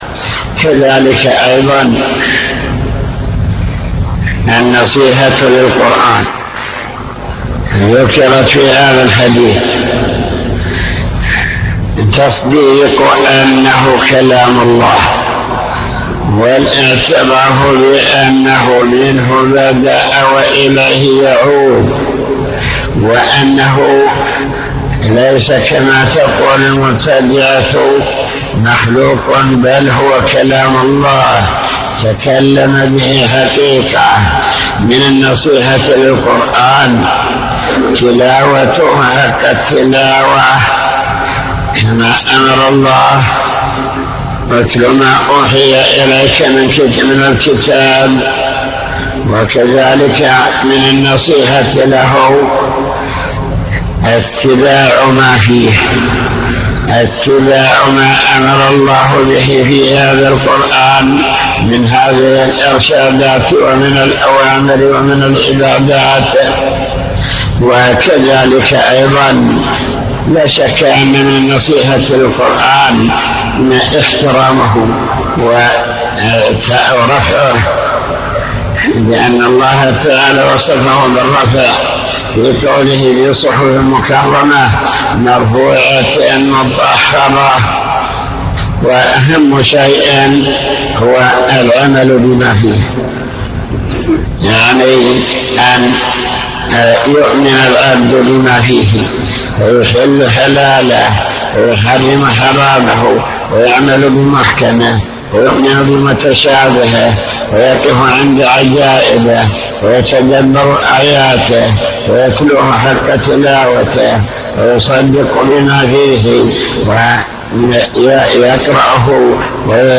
المكتبة الصوتية  تسجيلات - كتب  شرح كتاب بهجة قلوب الأبرار لابن السعدي شرح حديث الدين النصيحة